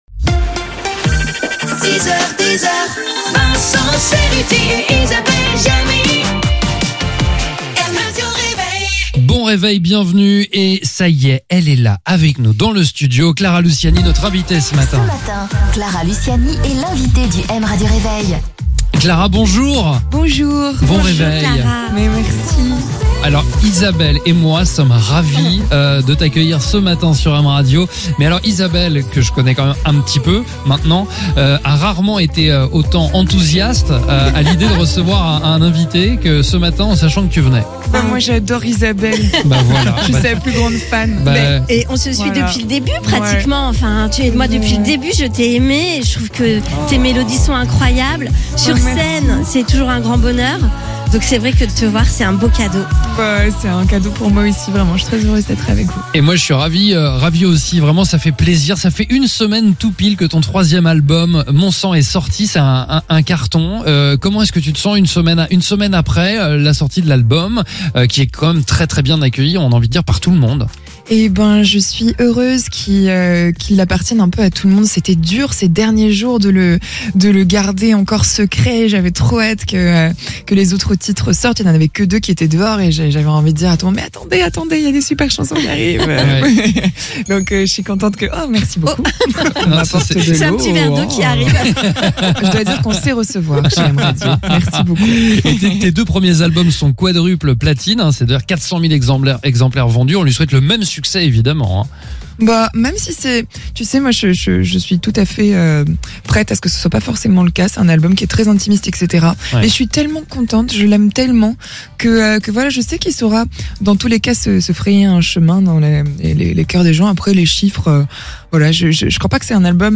podcast-mrr-itw-clara-luciani-wm-37550.mp3